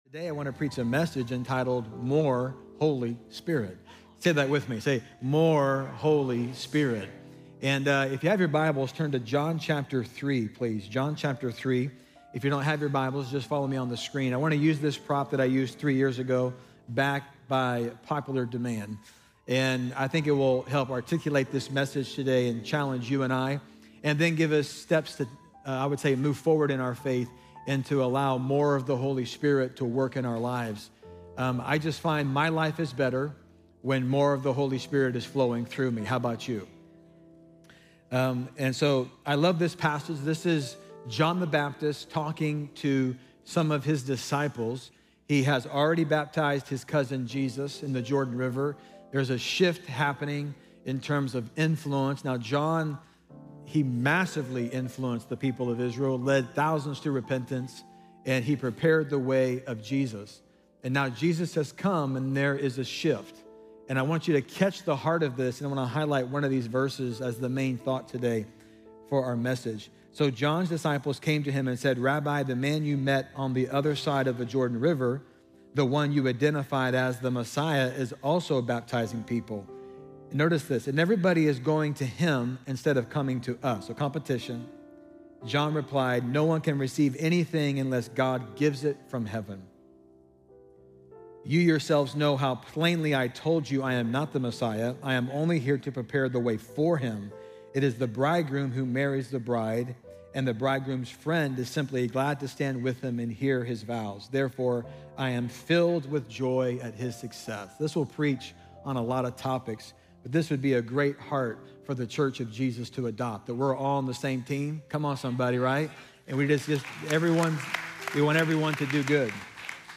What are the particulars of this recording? Play Rate Listened List Bookmark Get this podcast via API From The Podcast Enjoy powerful messages each week from City Church For All Nations in Bloomington, Indiana.